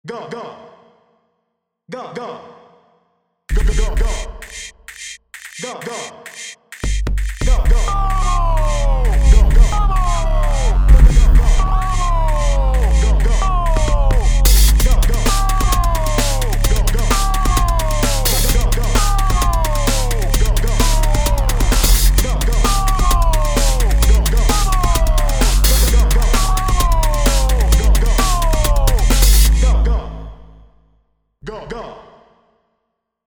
Im letzten Audiodemo hören Sie vier PunchBox-Instrumente. Zwei davon spielen die Schreie aus Kwaya ab. Um Abwechslung hineinzubringen habe ich den Sample-Start und die Tonhöhe moduliert. PunchBox 3 steuert eine metallisch schleifende Hi-Hat bei, die aus der Factory-Library stammt (Sample „Bigband“ für das Modul Top), PunchBox 4 ergänzt das Ganze durch eine erdige Bassdrum. Hinzu kommt der bereits bekannte Beat aus Toontracks EZdrummer, hier allerdings auf Hi-Hat und Snare reduziert. Extern habe ich die beiden PunchBox-Kanäle mit den Kwaya-Stimmen mit Eventides Tverb und Native Instruments Replika XT bearbeitet, um ein wenig Tiefenstaffelung zu erzeugen.